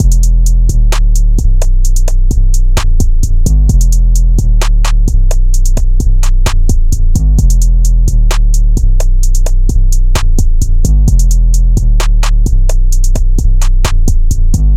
Metro type drum loop 130 bpm.wav